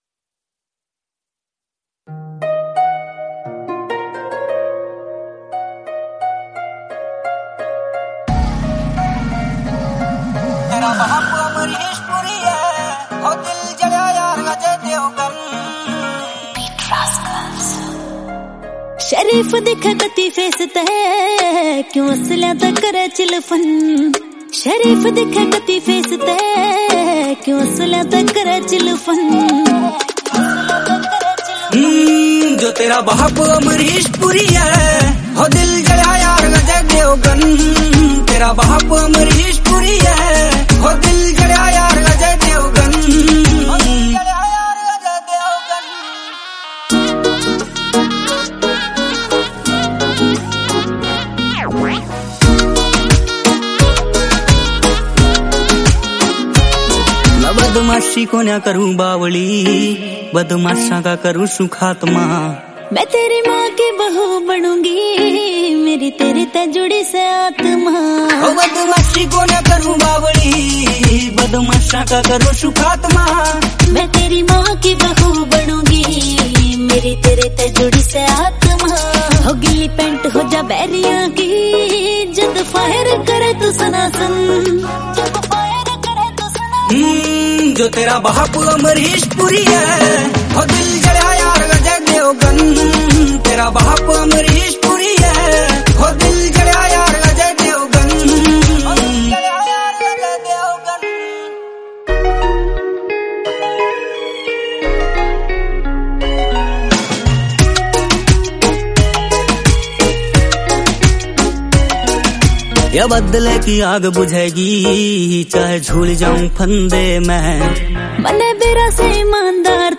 Category: Haryanvi